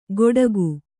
♪ goḍagu